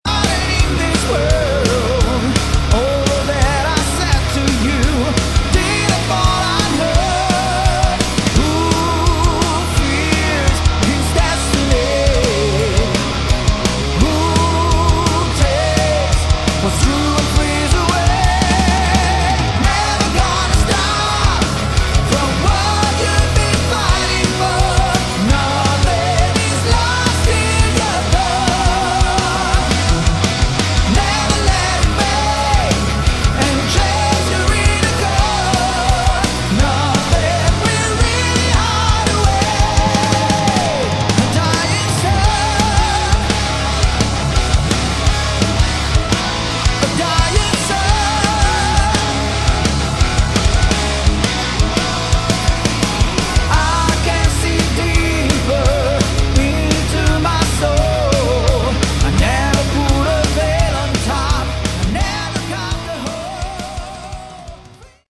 Category: Hard Rock
vocals, keyboards
guitar
bass
drums